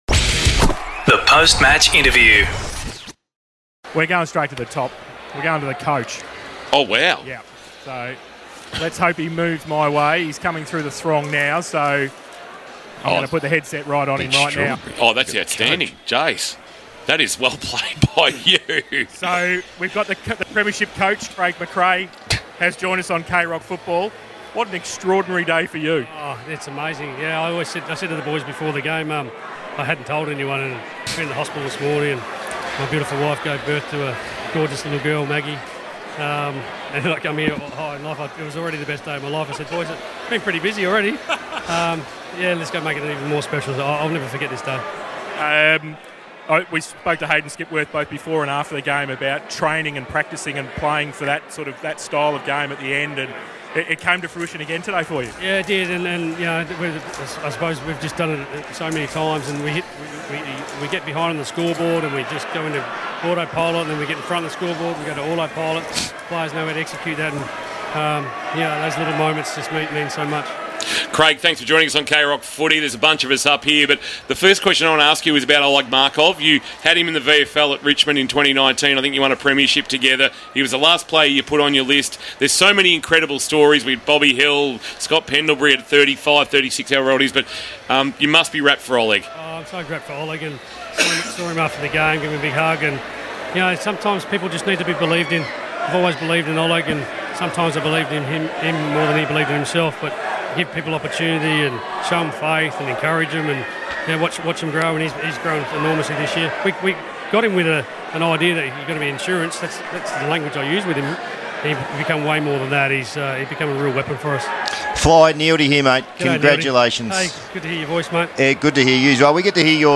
2023 - AFL - GRAND FINAL - COLLINGWOOD vs. BRISBANE: Post-match interview - Craig McRae (Collingwood senior coach)